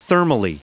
Prononciation du mot thermally en anglais (fichier audio)
Prononciation du mot : thermally